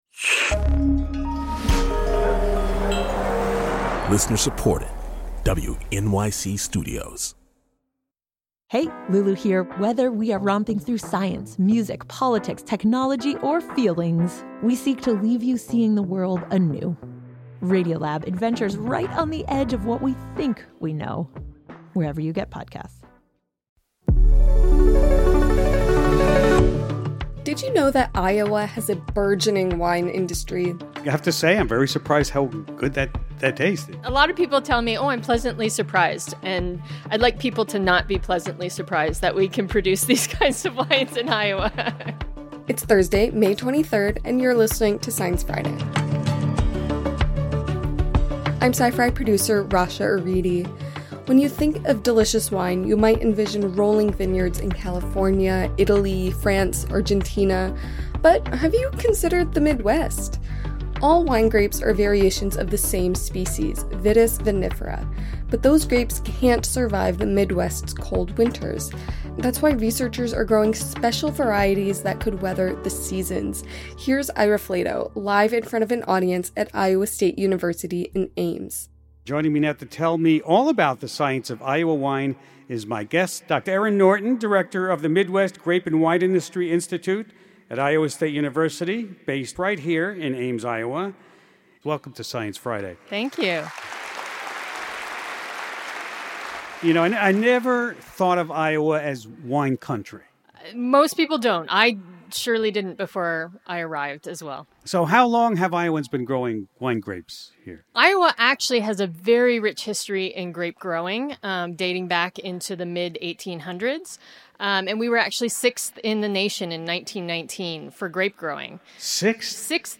Onstage in Ames, Iowa